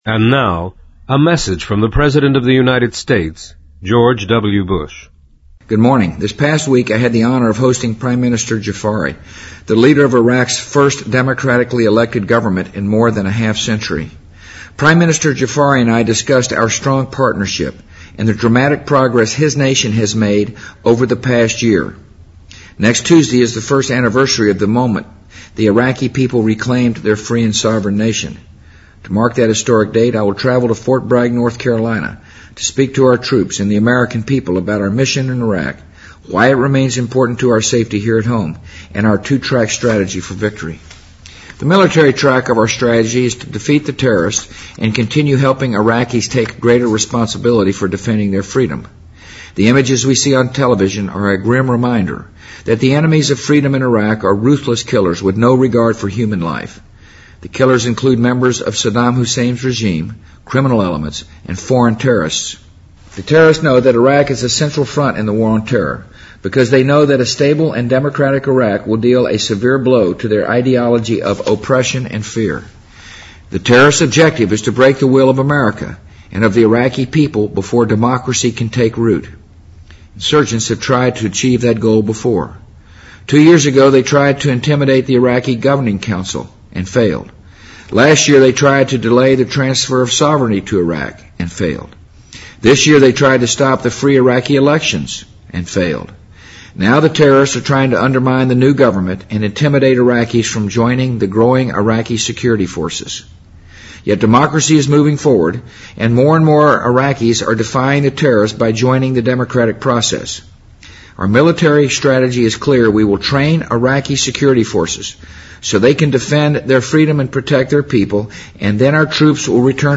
【美国总统电台演说】2005-06-25 听力文件下载—在线英语听力室